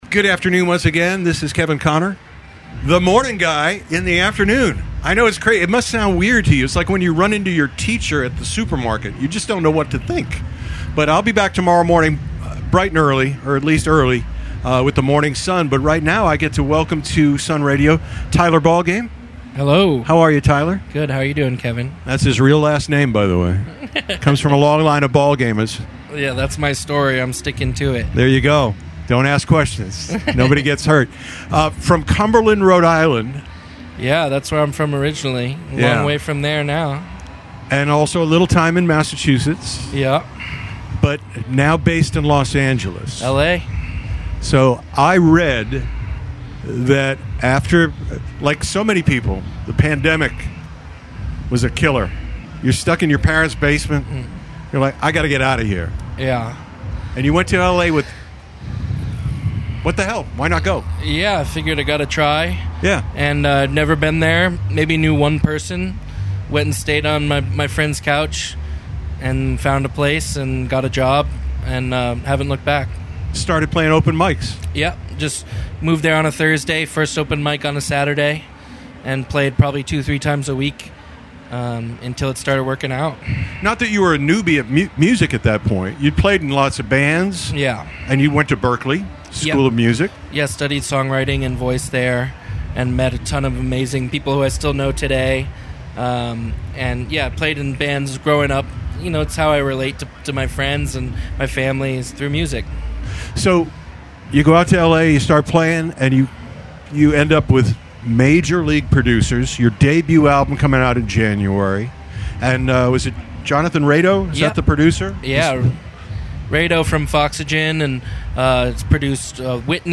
When he wasn’t running around the field catching amazing bands play live, he interviewed them in the Sun Radio Solar Powered Saloon!